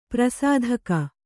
♪ prasādhaka